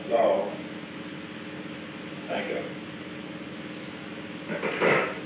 Secret White House Tapes
Location: Executive Office Building
An unknown person talked with the President.